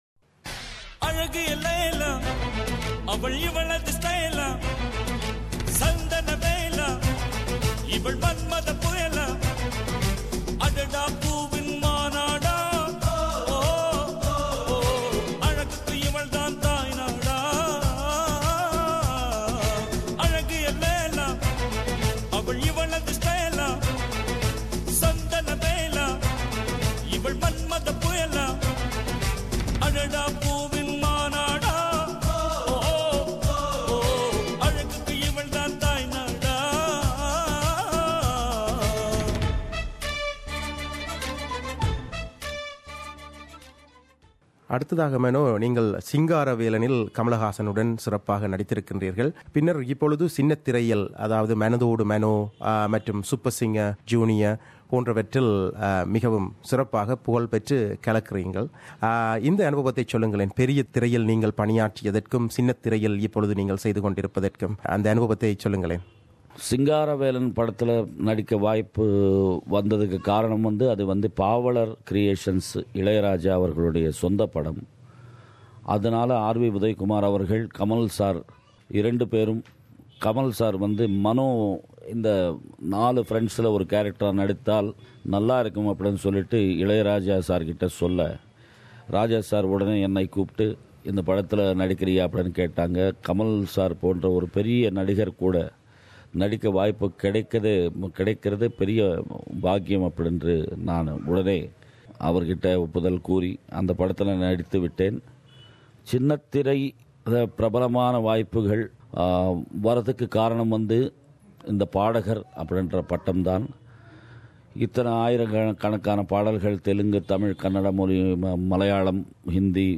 பாடகர் மனோவுடன் அவரது இல்லத்தில் ஒரு பிரத்தியேகச் சந்திப்பு - பாகம் 2